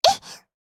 Taily-Vox_Jump_jp.wav